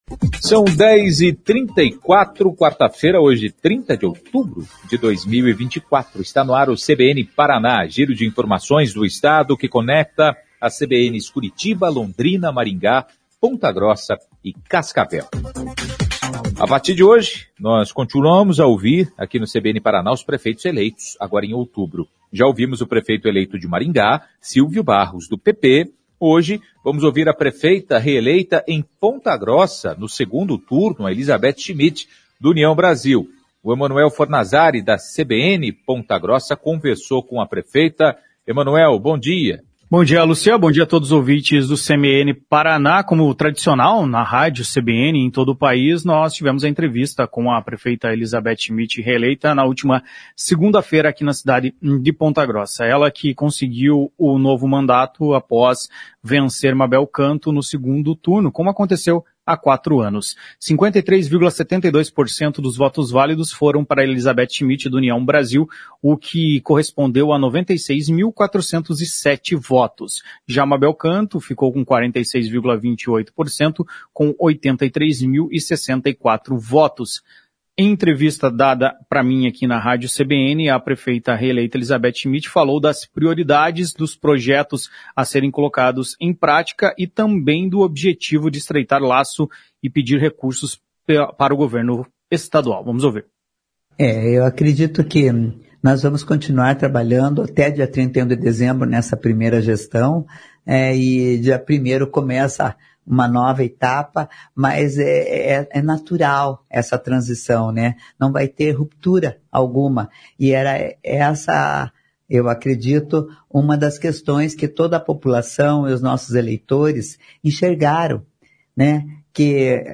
Prefeita eleita em Ponta Grossa, Elizabeth Schmidt fala das prioridades para gestão 2025